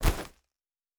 Fantasy Interface Sounds
Bag 09.wav